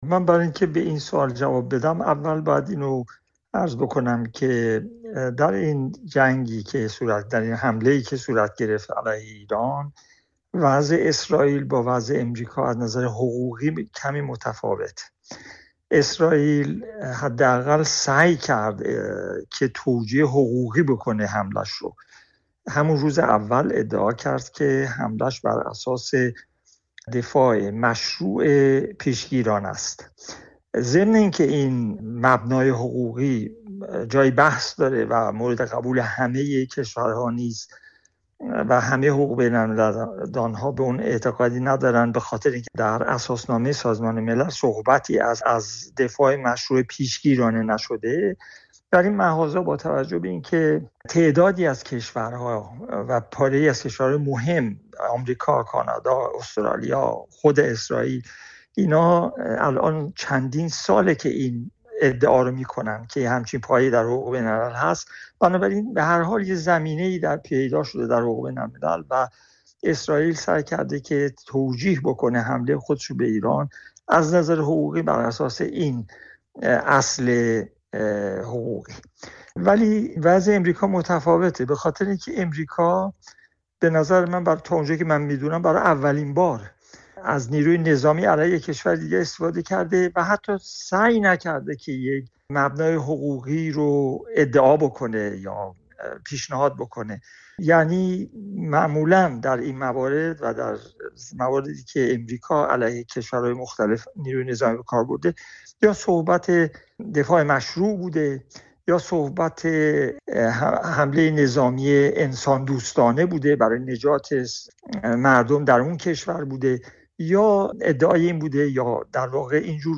در گفت وگو با رادیوفردا